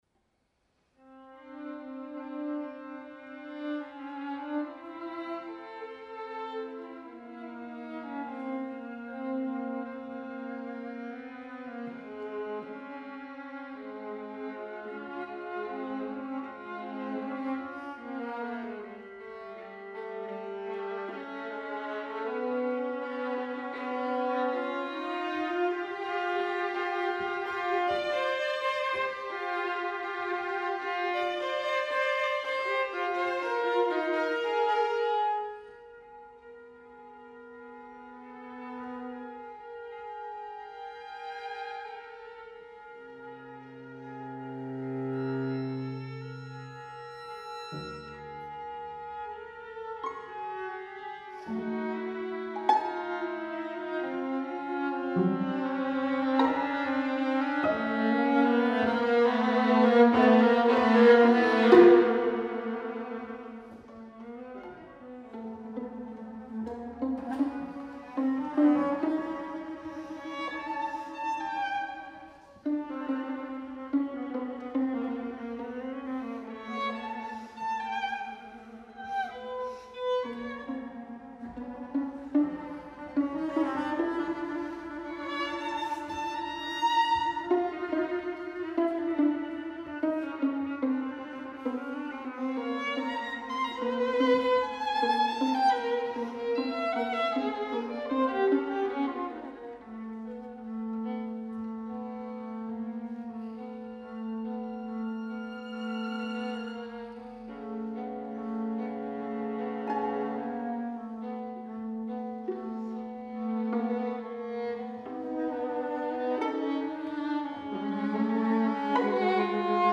Venue: St. Brendan’s Church
Instrumentation: 2vn, va, vc Instrumentation Category:String Quartet